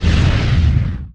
metinstone_drop1.wav